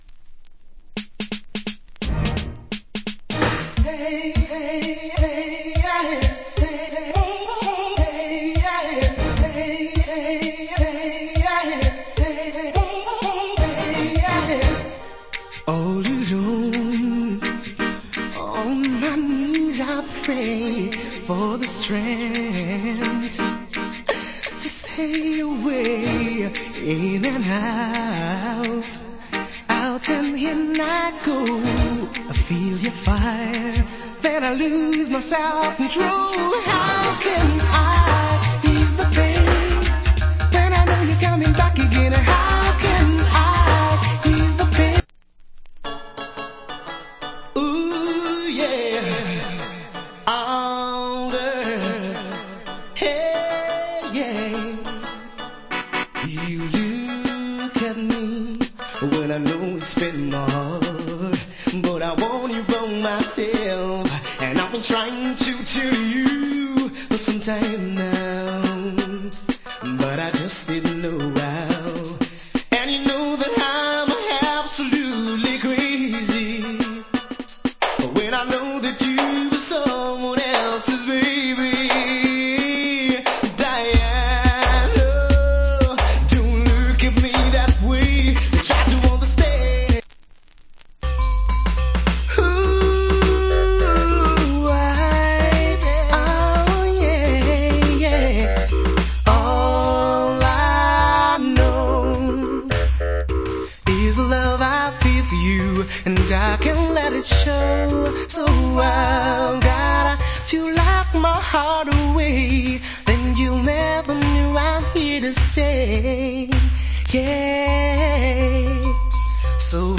90's. Nice Dancehall Vocal ALbum.